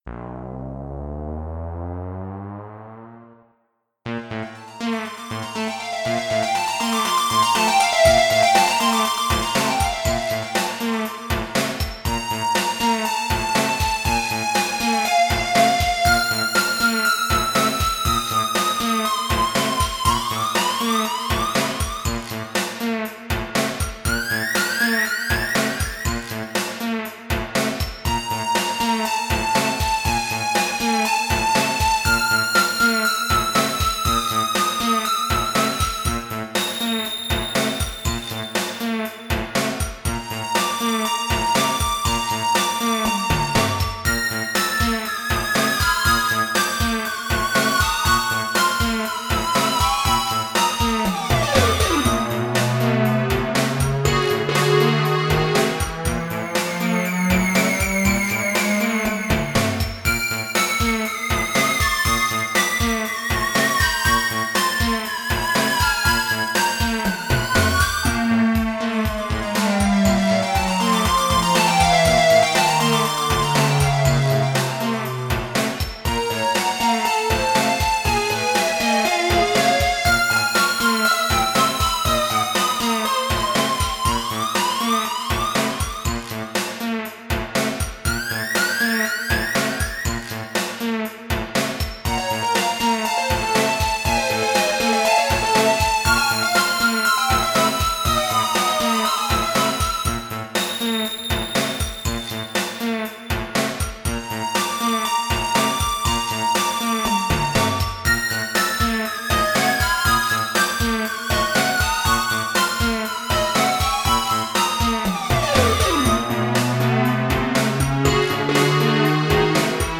game BGM-like classical/folk and original music in mp3